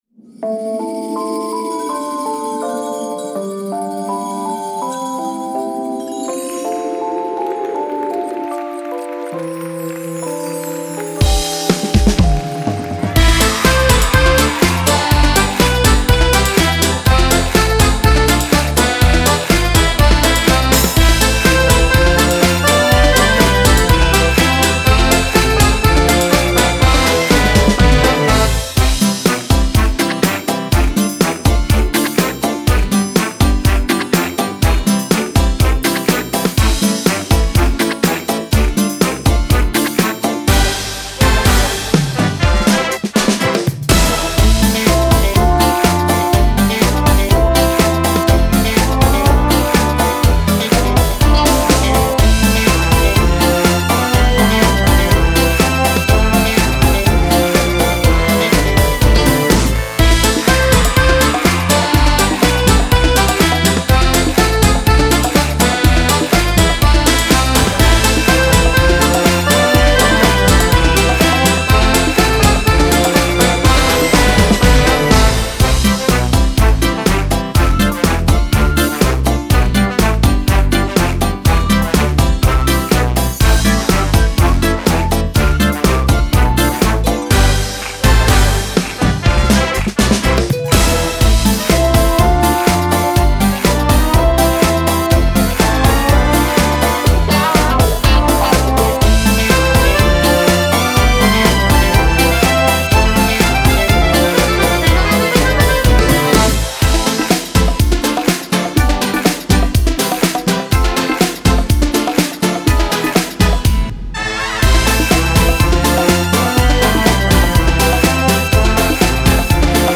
• Качество: Хорошее
• Категория: Детские песни
минусовка